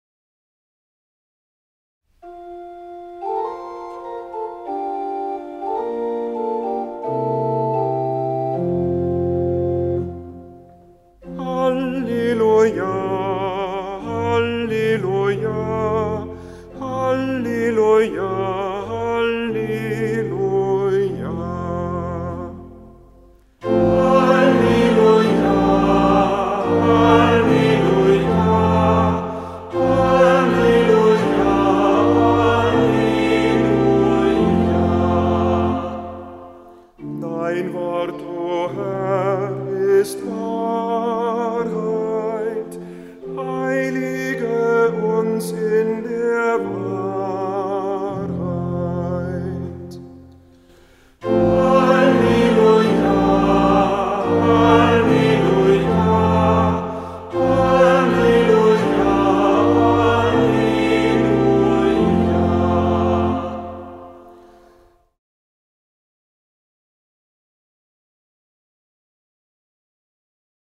Hörbeispiele aus dem Halleluja-Büchlein